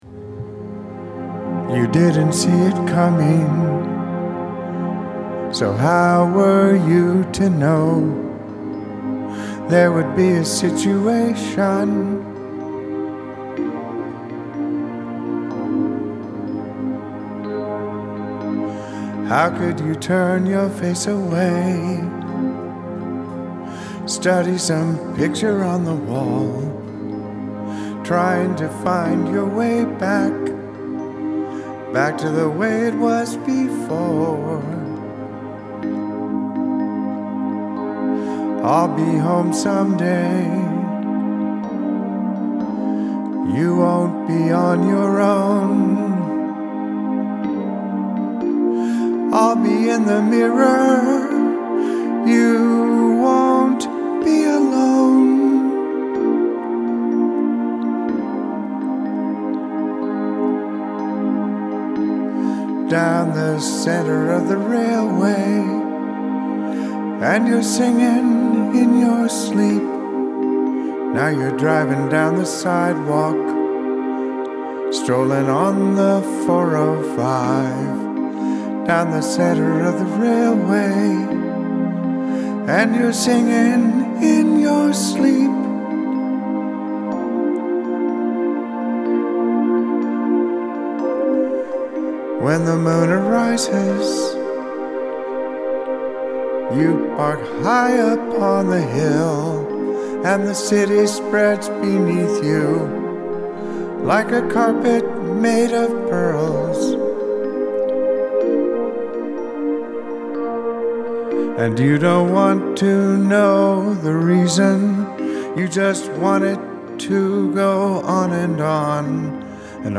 I cannot find the recording so I redid the backing, turning it into more of an ambient piece. I’m trying to figure out how to do the percussion I hear in my head for it.